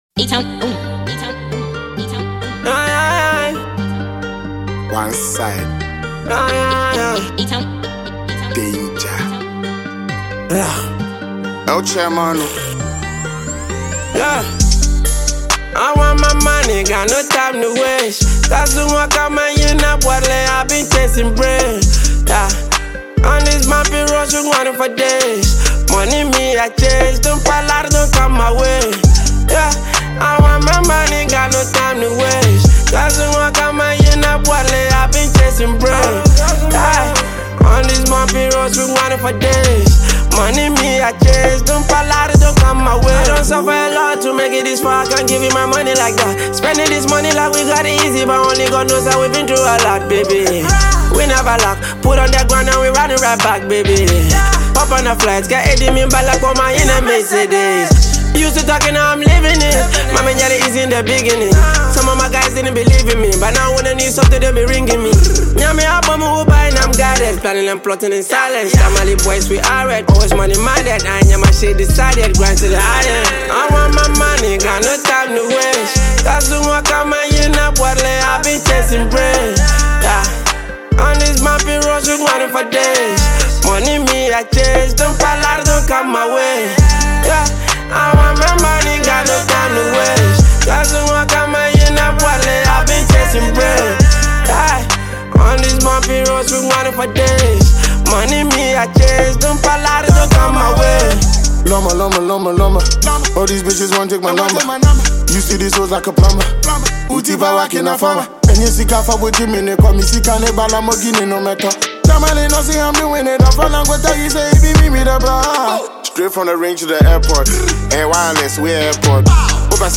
Ghana Music
dynamic rap verses
With its catchy melodies and meaningful lyrics